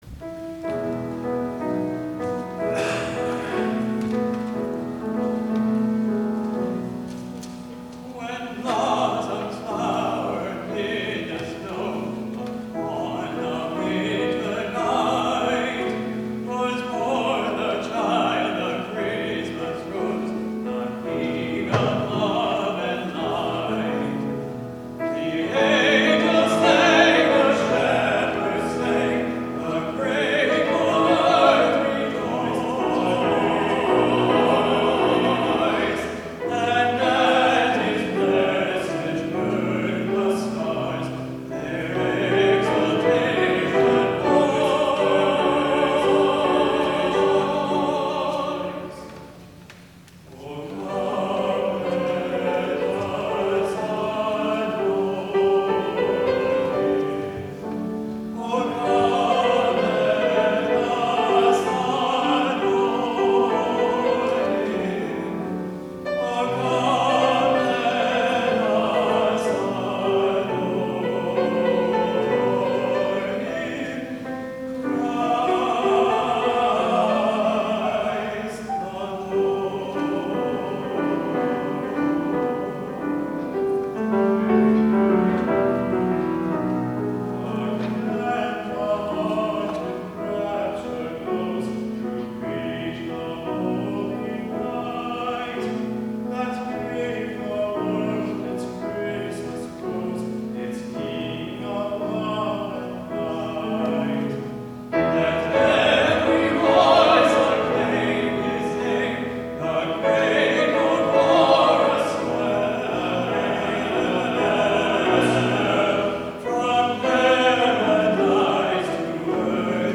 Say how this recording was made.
WORSHIP DECEMBER 25 2016